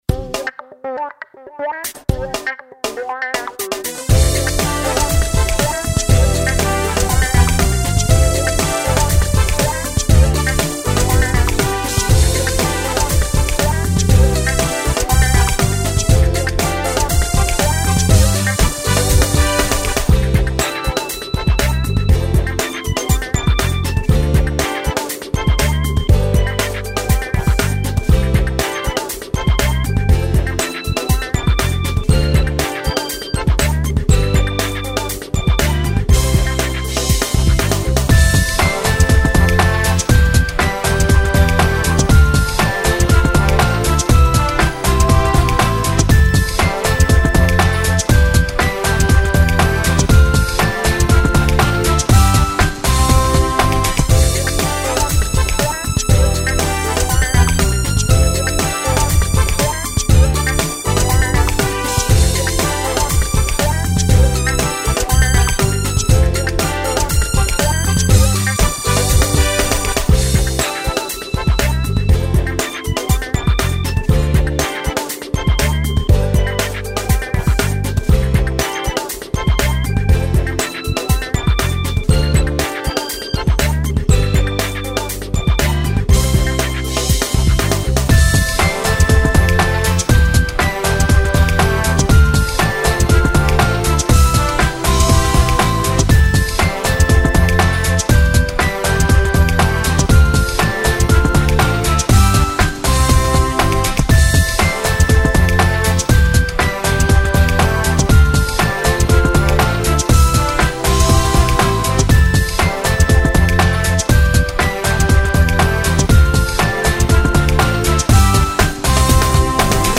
阿不倒(純伴奏版) | 新北市客家文化典藏資料庫